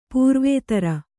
♪ pūrvētara